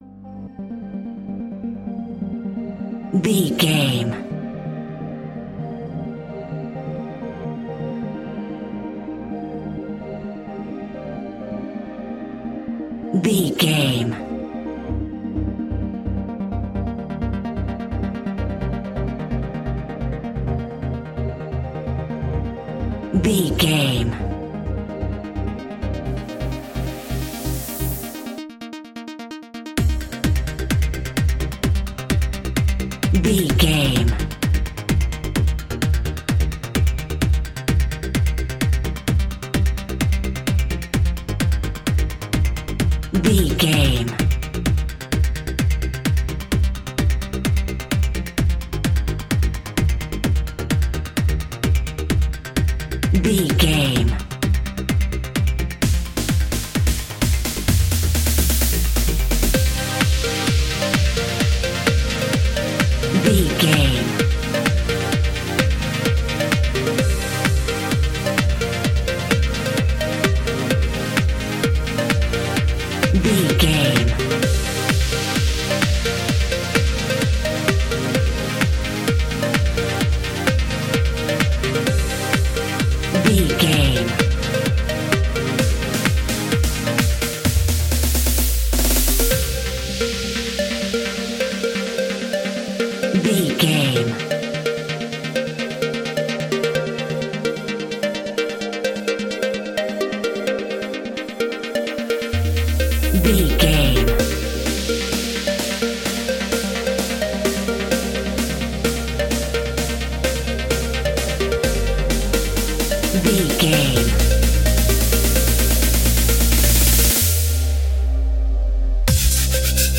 Epic / Action
Fast paced
Aeolian/Minor
aggressive
powerful
dark
intense
energetic
driving
synthesiser
drums
drum machine
futuristic
breakbeat
synth leads
synth bass